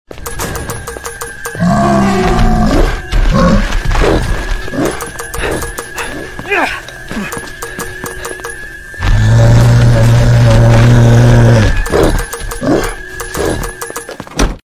The Scary Monster is running sound effects free download
The Scary Monster is running behind me .